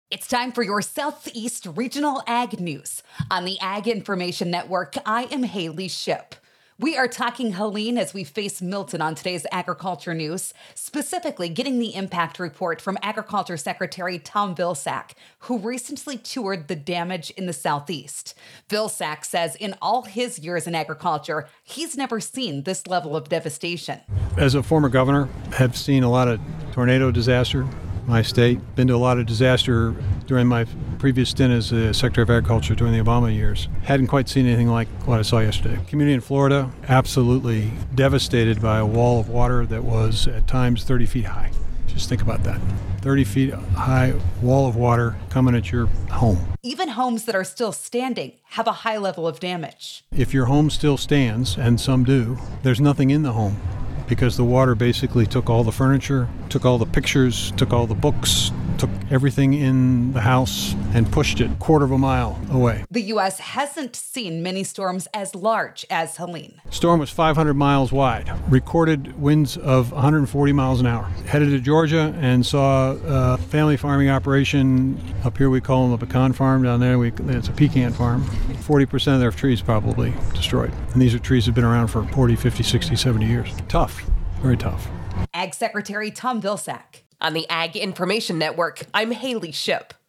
Ag Secretary Tom Vilsack.